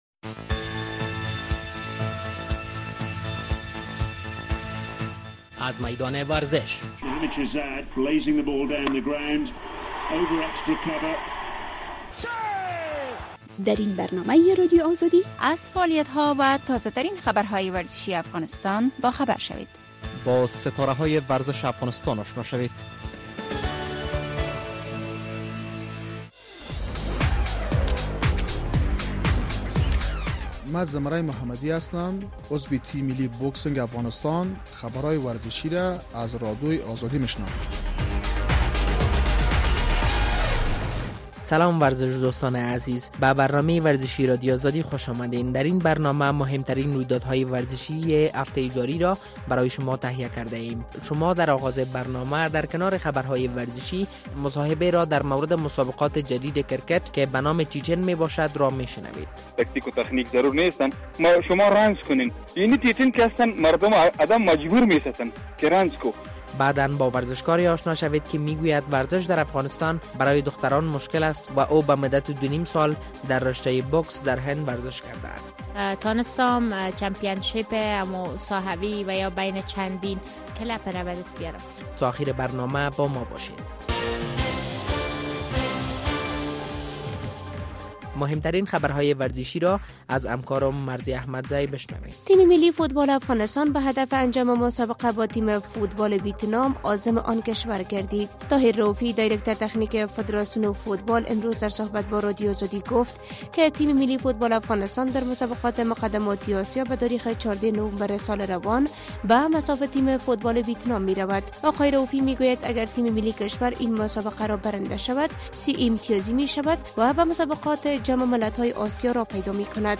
در برنامه ورزشی این هفتۀ رادیو آزادی در کنار مهترین خبرهای ورزشی در مورد مسابقات جدید کرکت مصاحبه را ...